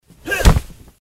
SFX拳头打人音效下载
SFX音效